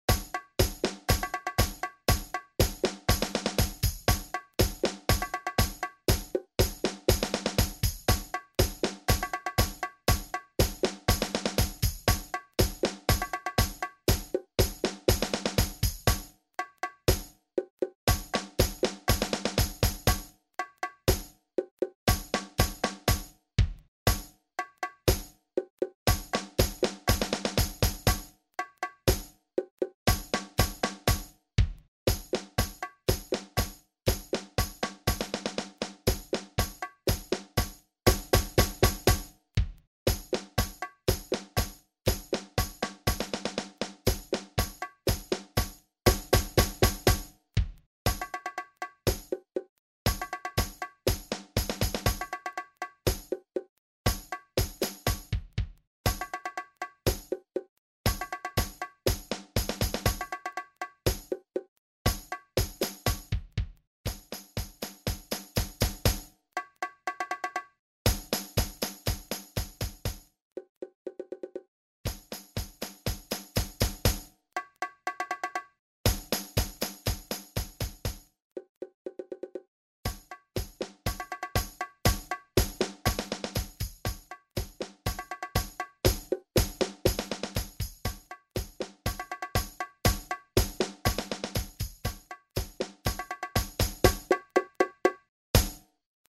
Junior Percussion
Noten für Junior Percussion Ensemble - stimmlose Percussion.